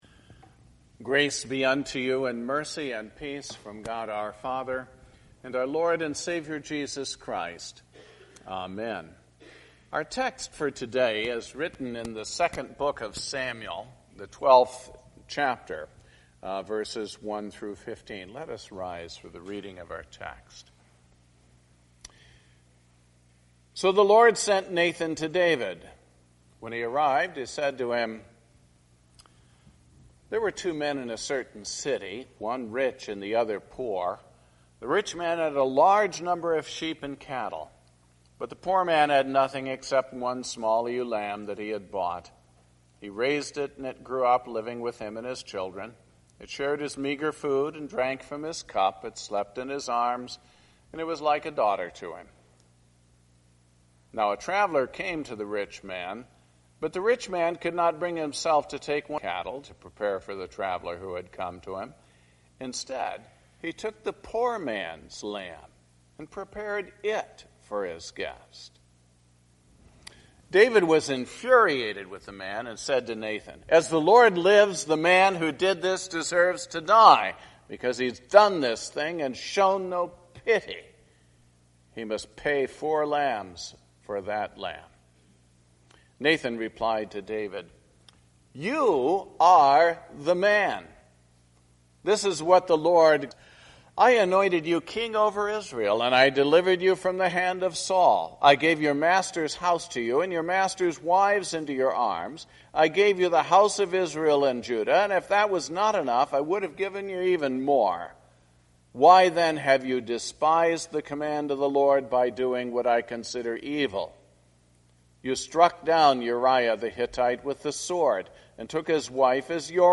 Sermon based on the office of the keys and confession: 2 Samuel 12:1-15.